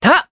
Evan-Vox_Attack4_jp_b.wav